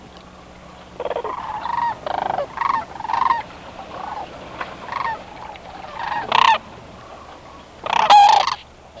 shcrane.wav